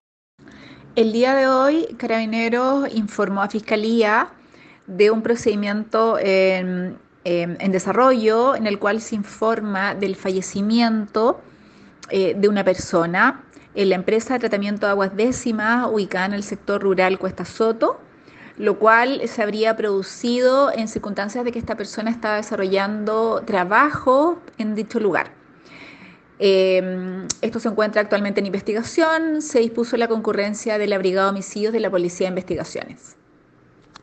Por su parte la Fiscal Maria Consuelo Oliva entrego los primeros antecedentes del lamentable hecho…
fiscal-consuelo-oliva.m4a